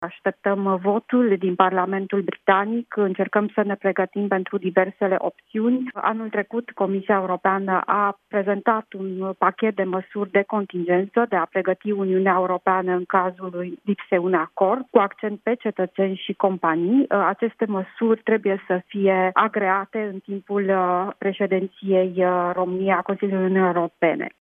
Într-un interviu acodat Europa FM, ambasadorul  României pe lângă Uniunea Europeană,  Luminiţa Odobescu, a explicat care sunt primele evenimente majore ale președinției României din mandatul de șase luni.